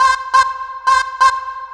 FX 138-BPM C.wav